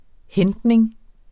Udtale [ ˈhεndneŋ ]